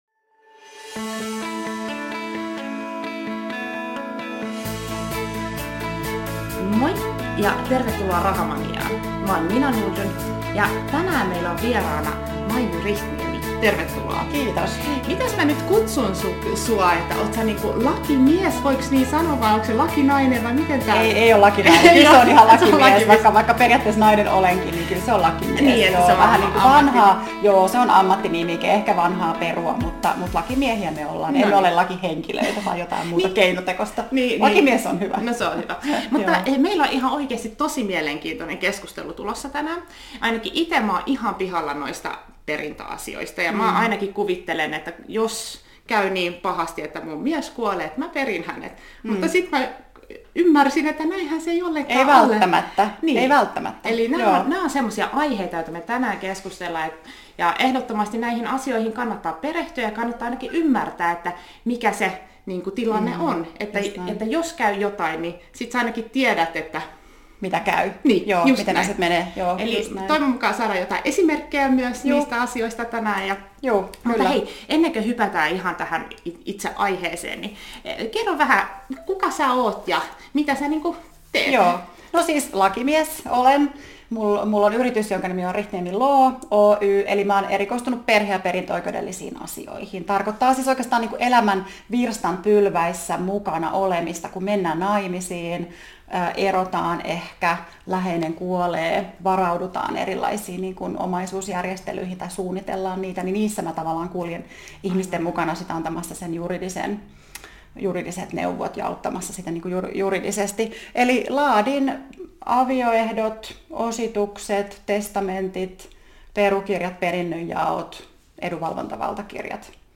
-podcastissa haastateltavana